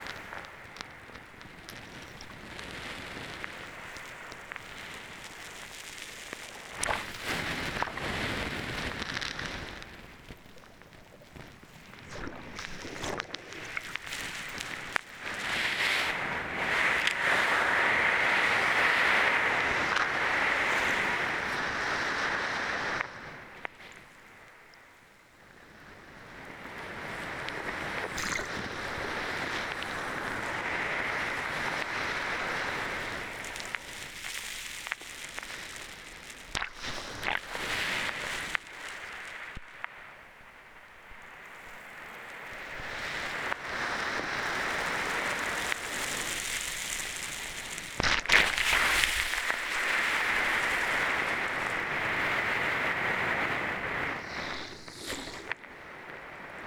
260305_scheveningen_hydrophones
Recorded with zoom H6 and two hydrophones